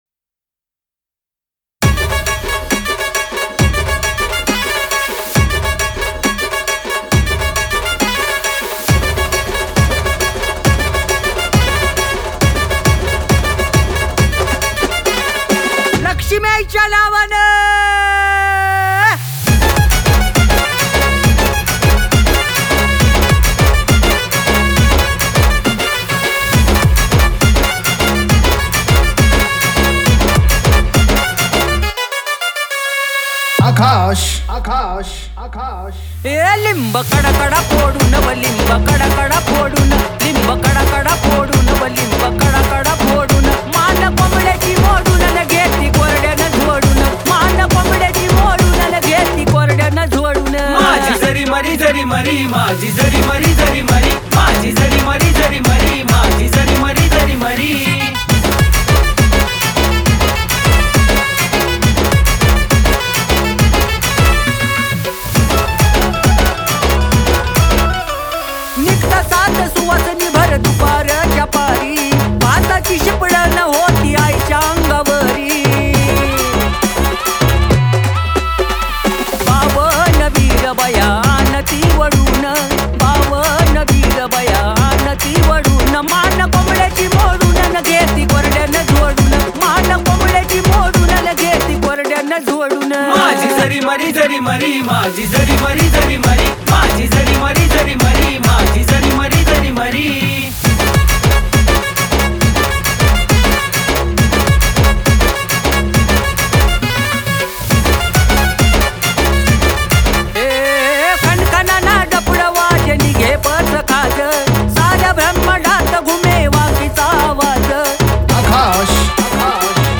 Category : Navratri Dj Remix Song